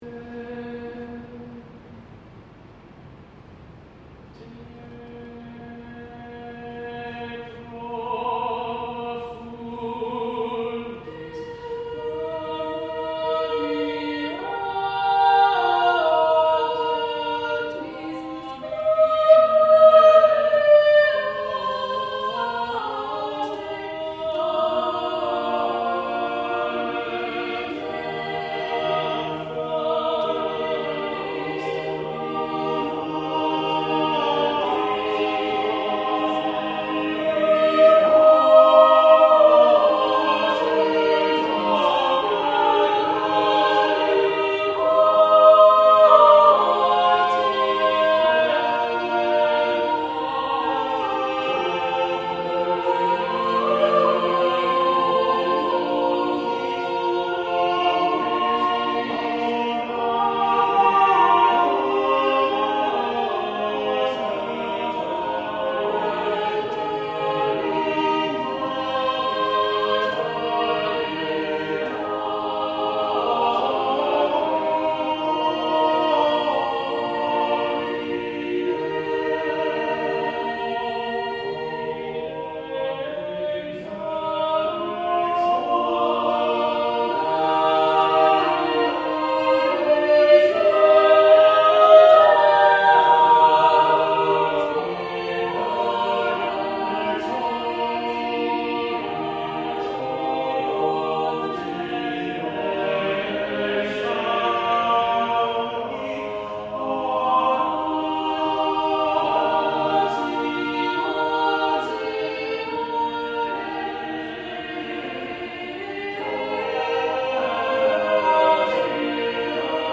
St Stephen’s Cathedral Schola